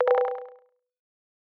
HTC Desire Bildirim Sesleri